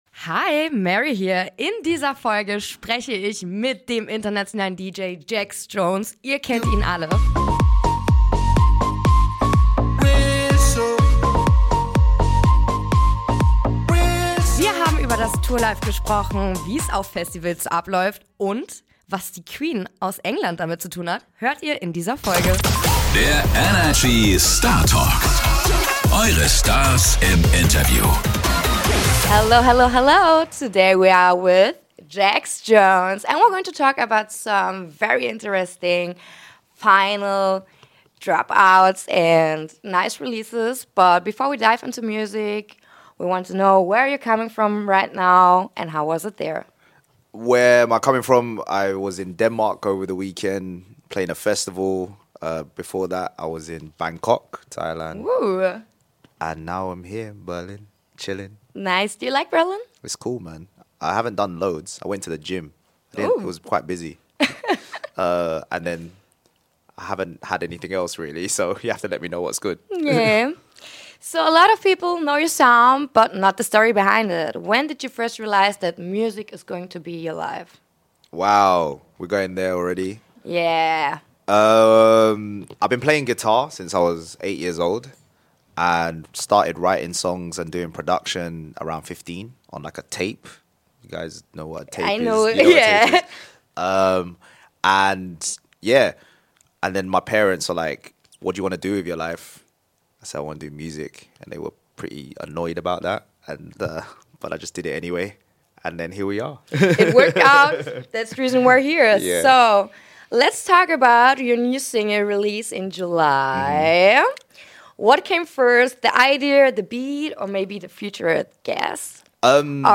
In dieser Folge ist der britische DJ und Producer mit nigerianischen Wurzeln zu Gast und erzählt uns, welche Rolle seine Familie und seine Kultur in seiner Musik spielen. Außerdem verrät er, warum für ihn Blumen eine ganz besondere Bedeutung haben und was die Queen selbst mit seiner Karriere zu tun hat.